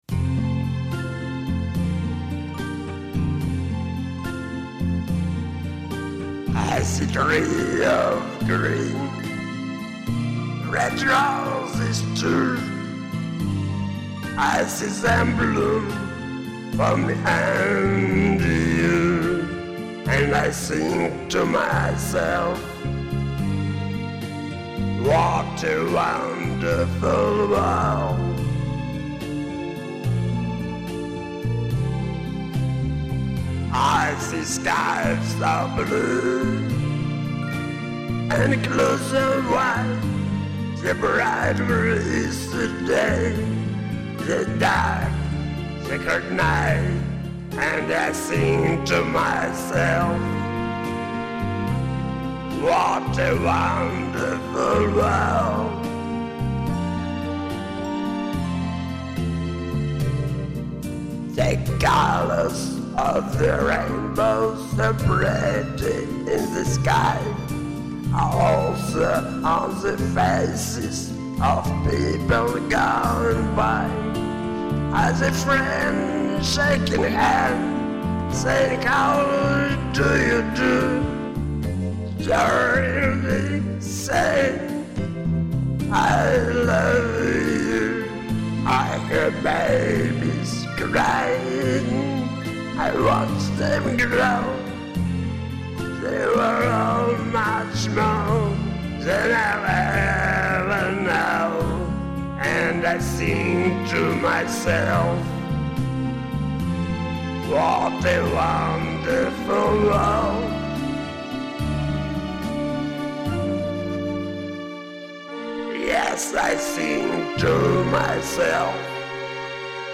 Джазовая классика!